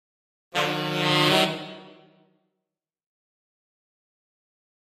Saxophone
Horn Section Criminal Increasing 5 Lower Type C